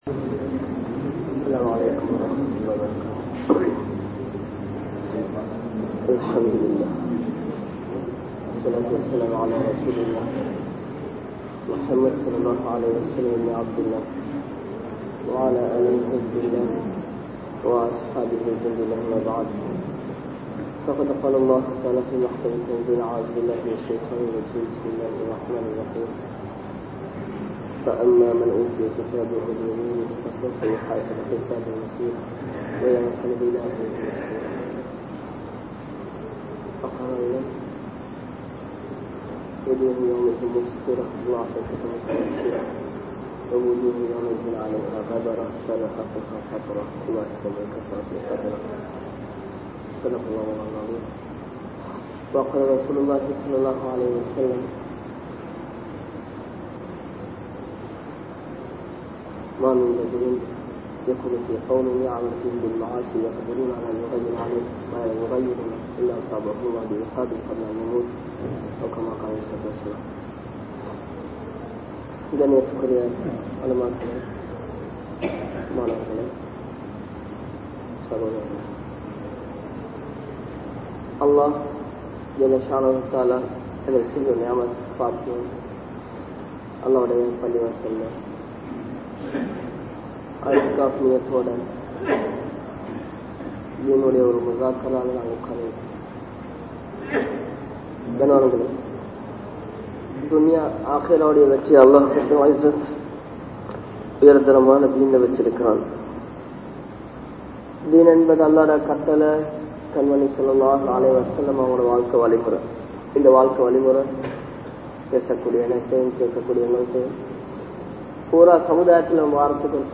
Unmaiyaana Muslim Yaar? (உண்மையான முஸ்லிம் யார்?) | Audio Bayans | All Ceylon Muslim Youth Community | Addalaichenai